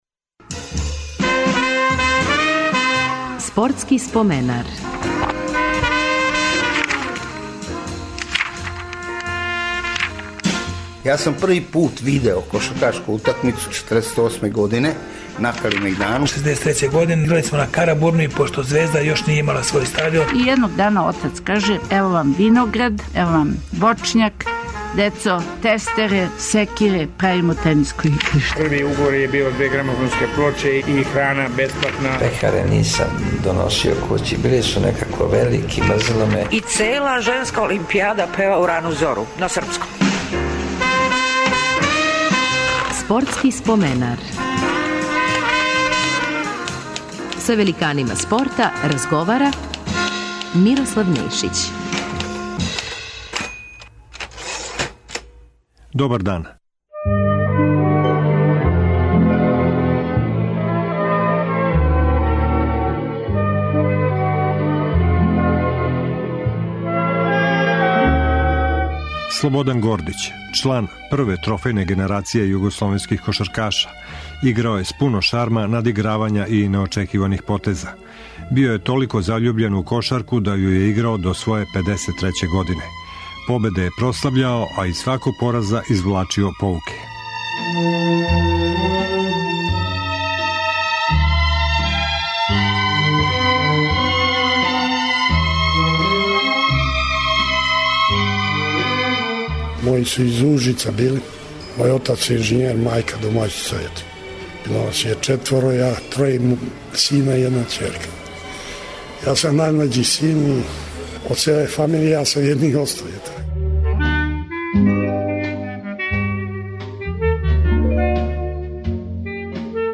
Гост нам је кошаркаш